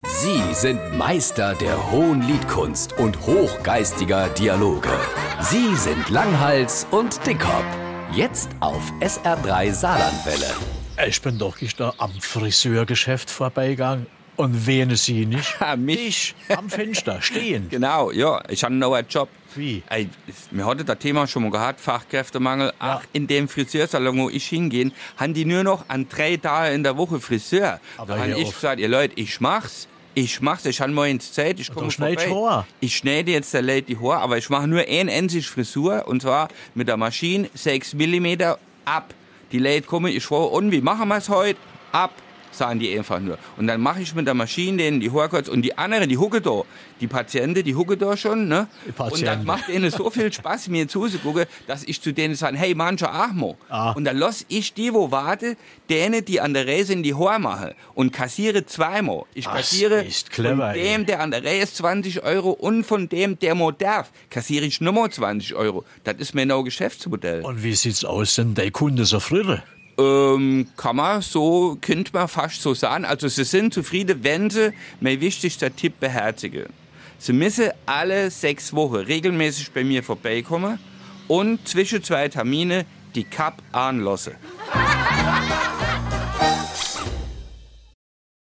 Die sprachlichen Botschafter Marpingens in der weiten saarländischen Welt. Philosophisch, vorder-, tief- und hintergründig lassen sie uns teilhaben an ihren mikrokosmischen An- und Einsichten.